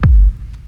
• Fat Steel Kick Drum Sample G Key 337.wav
Royality free kickdrum tuned to the G note. Loudest frequency: 117Hz
fat-steel-kick-drum-sample-g-key-337-ANR.wav